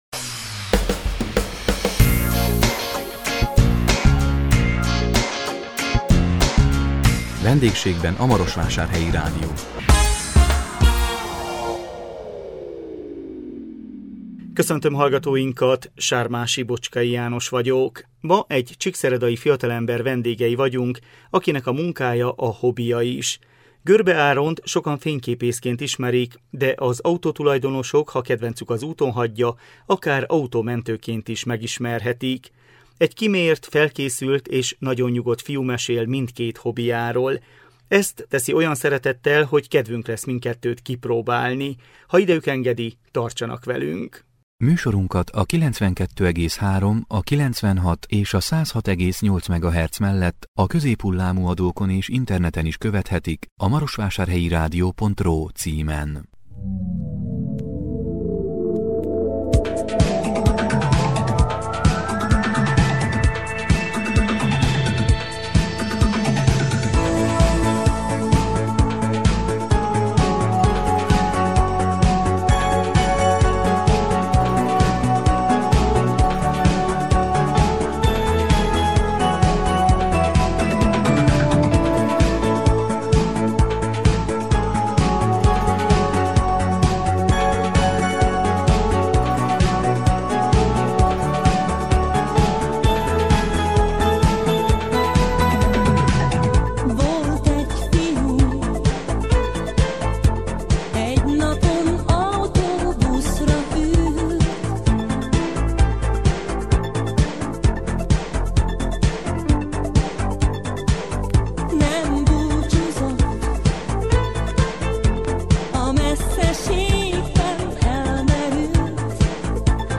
Egy kimért, felkészült és nagyon nyugodt fiú mesél mindkét hobbyjáról.